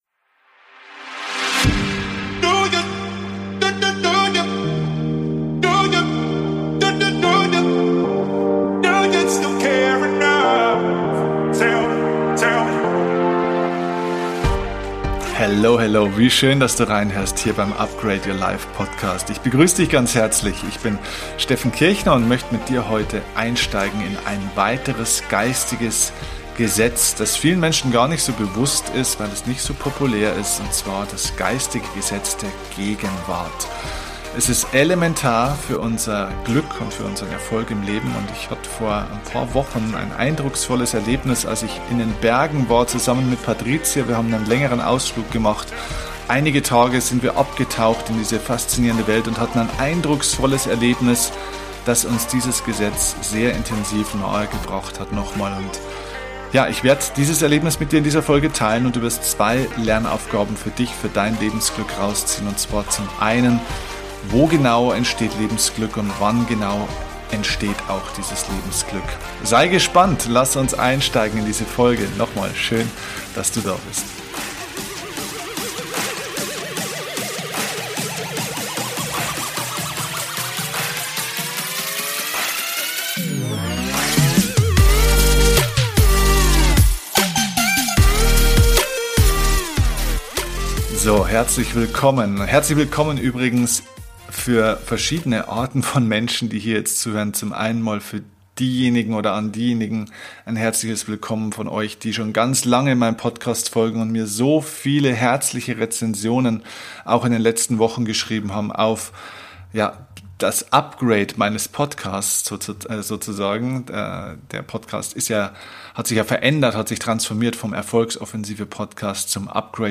#324 Das geistige Gesetz der Gegenwart | Meditation | Atemübung | Geistigen Gesetze ~ DIE KUNST ZU LEBEN - Dein Podcast für Lebensglück, moderne Spiritualität, emotionale Freiheit und berufliche Erfüllung Podcast
Es geht um die Macht des Augenblickes. Um diese zu spüren, habe ich eine kleine Meditation für Dich.